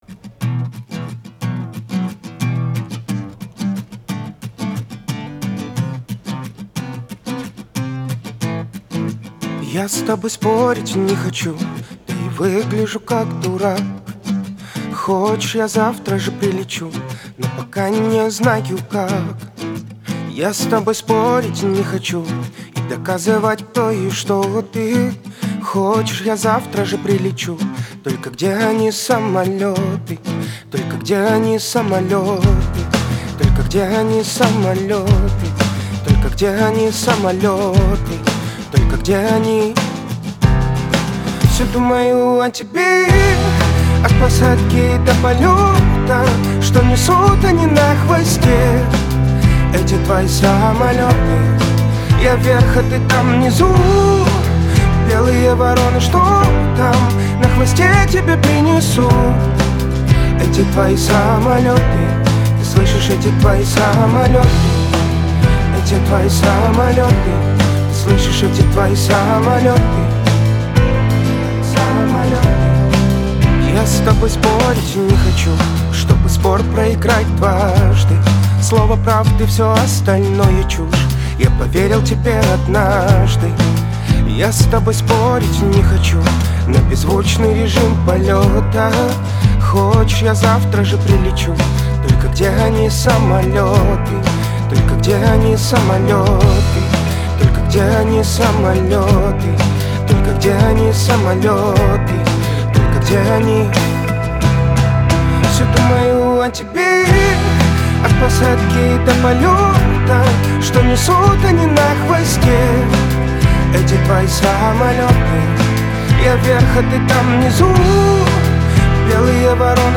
Дабстеп